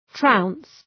Προφορά
{traʋns}